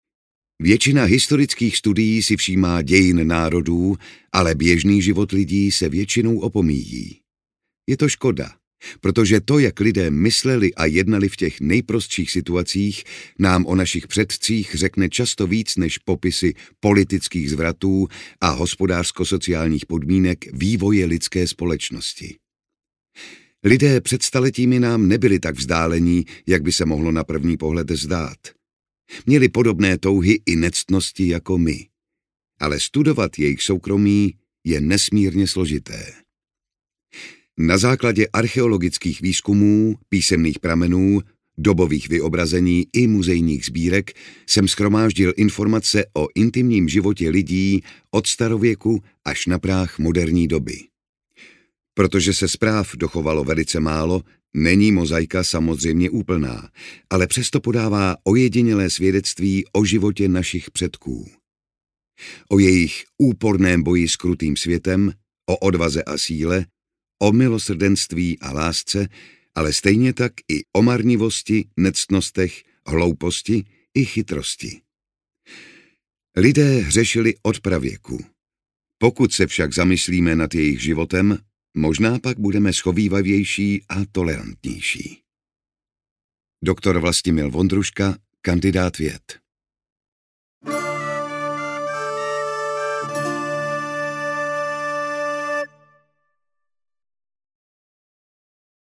Intimní historie od antiky po baroko audiokniha
Ukázka z knihy
• InterpretJan Šťastný
intimni-historie-od-antiky-po-baroko-audiokniha